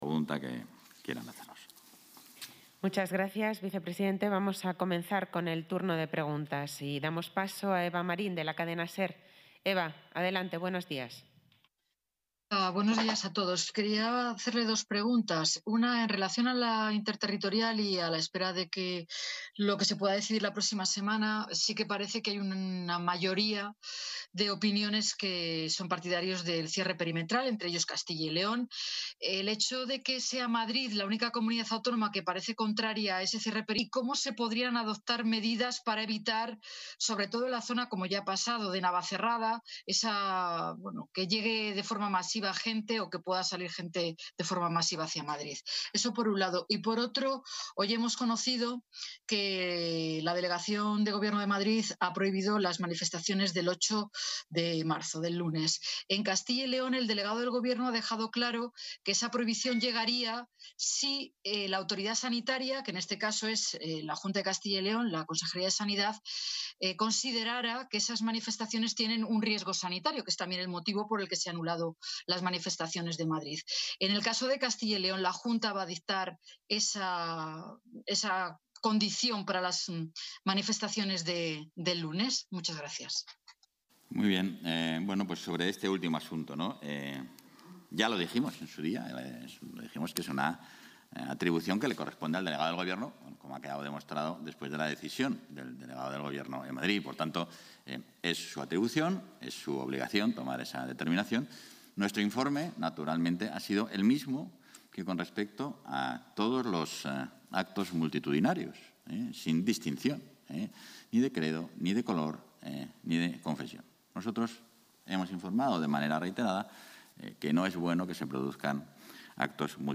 Intervención del vicepresidente y portavoz.